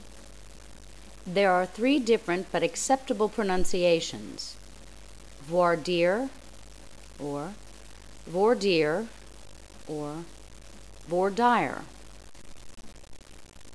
Voir Dire (vwahr deer or vor deer or vor dɪr)